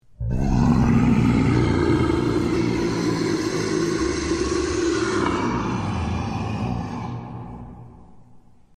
rugidodragon.mp3